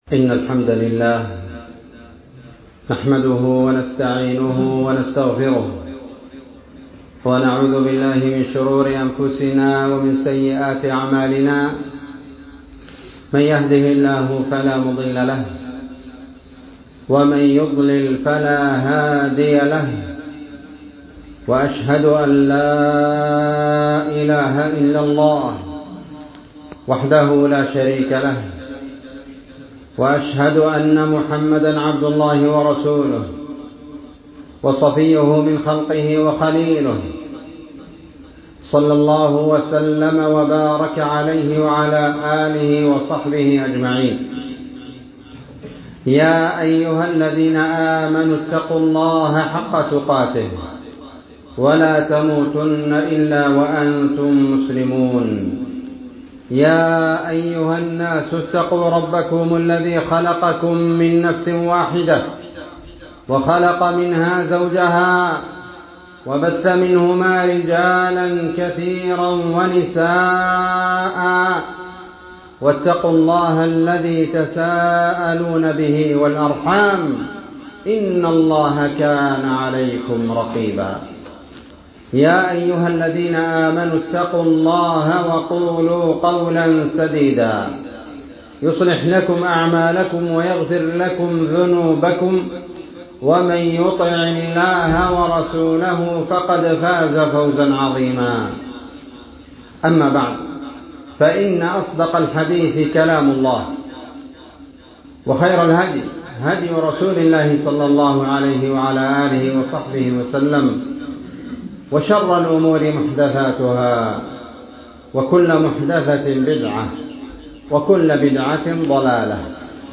خطبة
مصر - الجيزة - شارع الأهرام-مسجد الزهراء